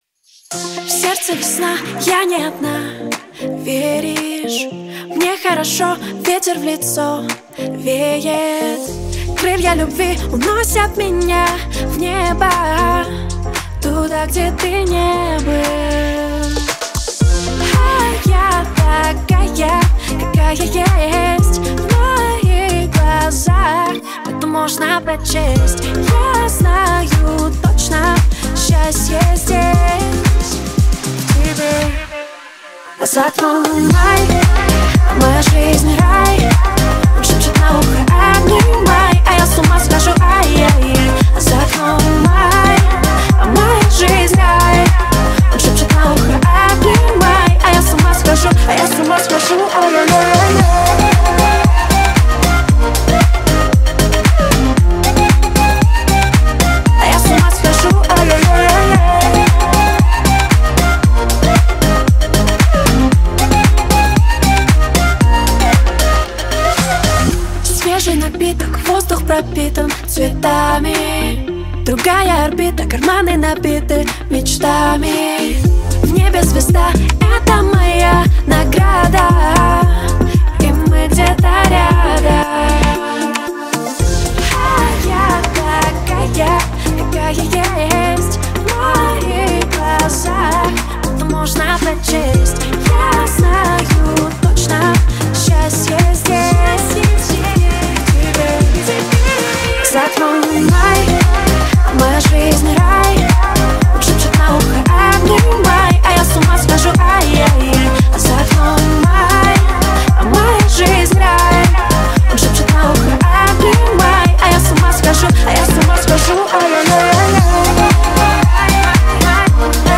Жанр: Казахские